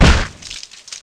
gore2.ogg